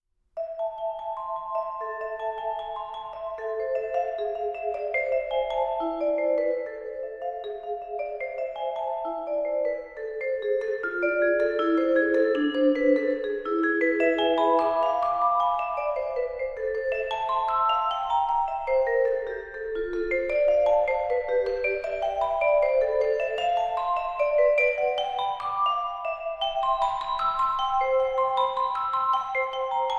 for Vibraphone